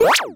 retro_enemy_attack_03.wav